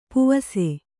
♪ puvase